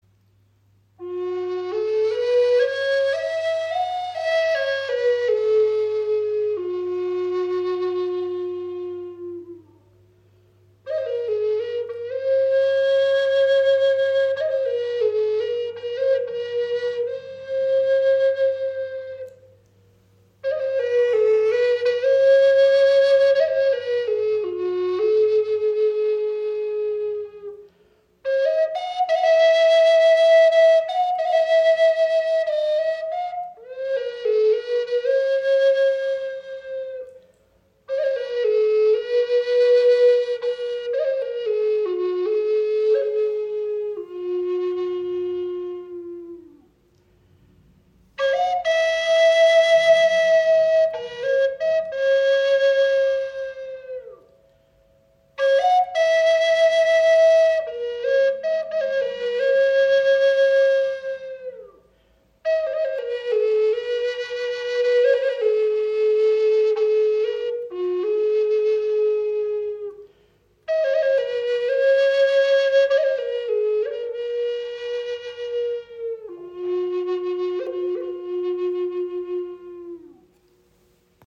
Gebetsflöte High Spirit in F# - Eagle im Raven-Spirit WebShop • Raven Spirit
Klangbeispiel
Diese High Spirit Flöte in F# mit einem Adler Windblock wurde aus Walnussholz erschaffen und mit biologischen Öl versiegelt.